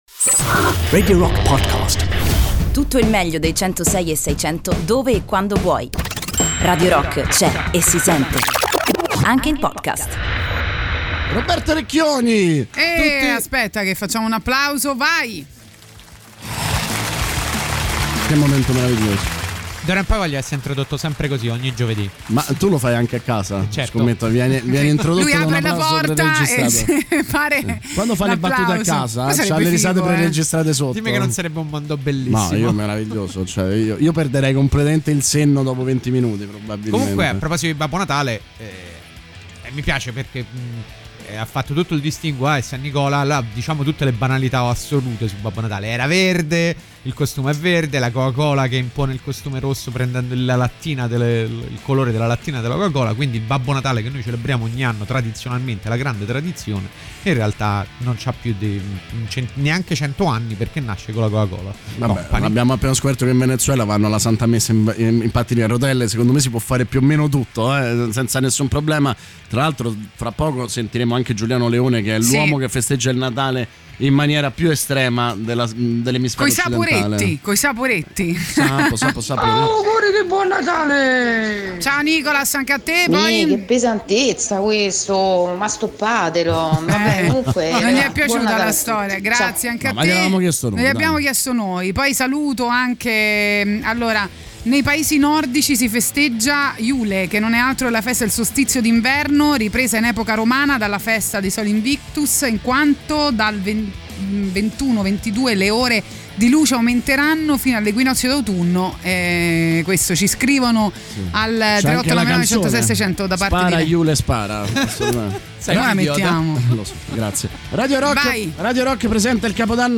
Intervista: Roberto Recchioni (25-12-19)